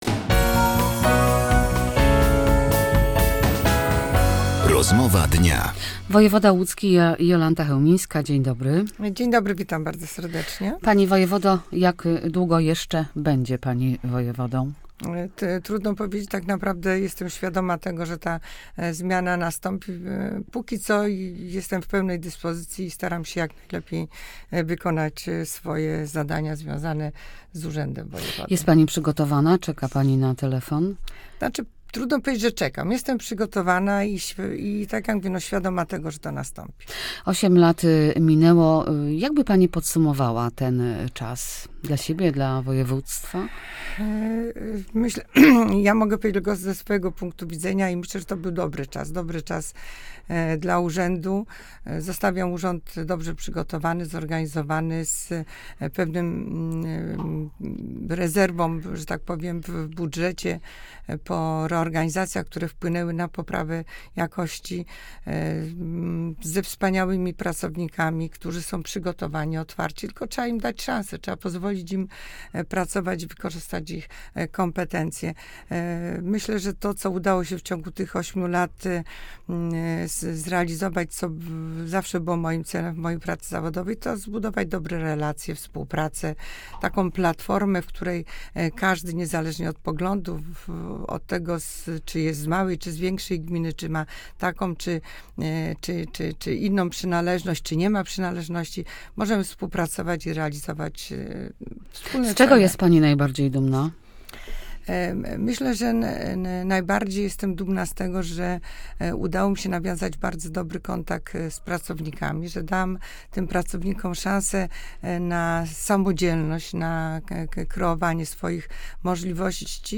w Rozmowie Dnia była Jolanta Chełmińska, wojewoda łódzki